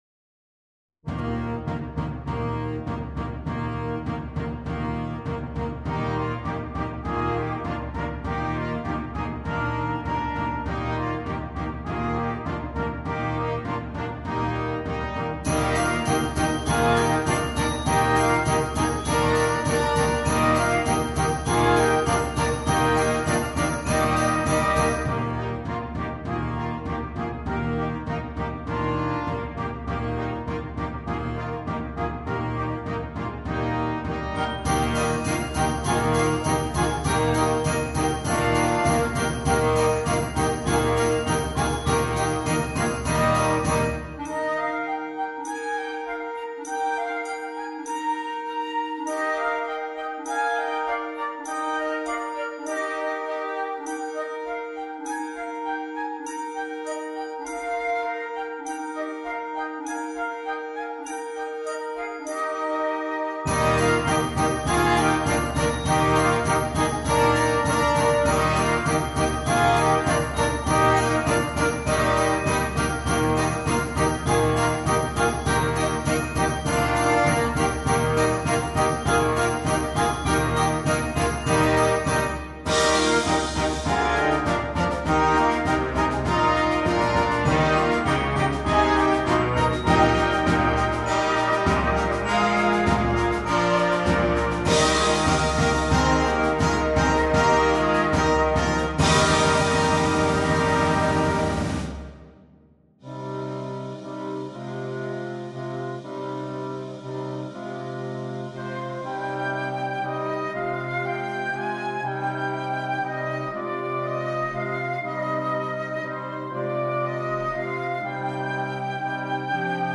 Per banda giovanile